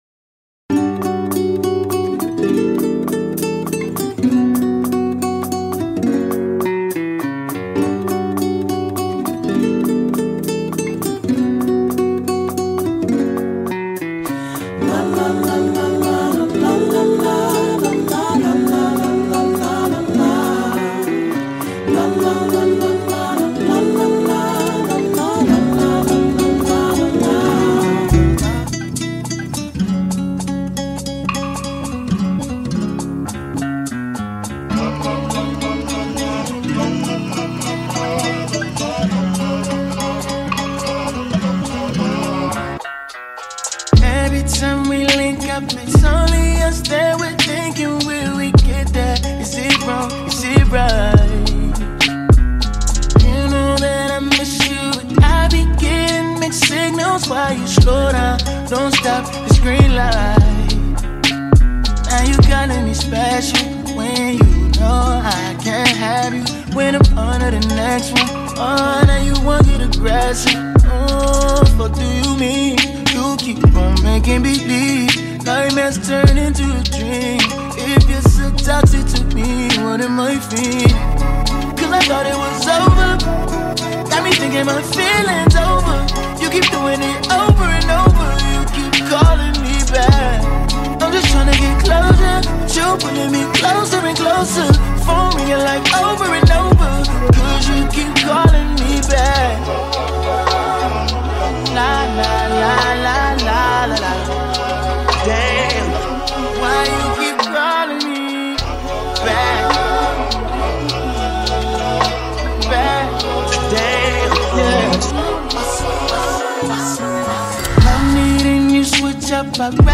A Mash-Up Remix